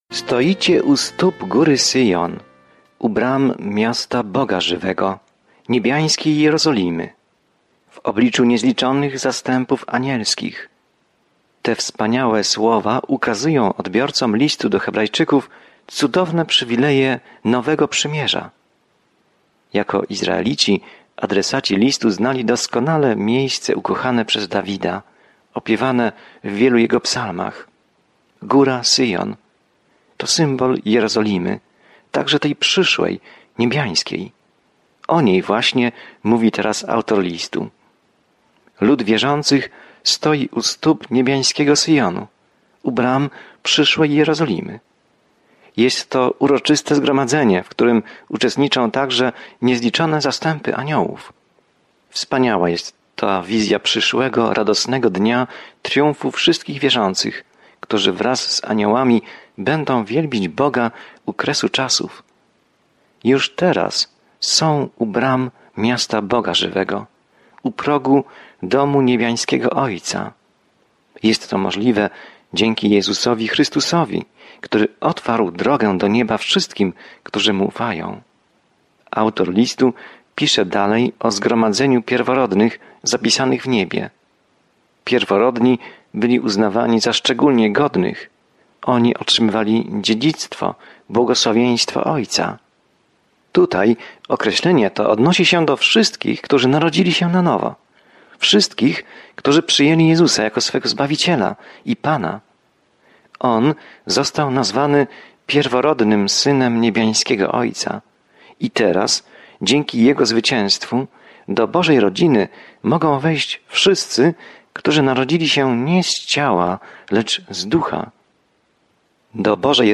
Codziennie podróżuj po Liście do Hebrajczyków, słuchając studium audio i czytając wybrane wersety słowa Bożego.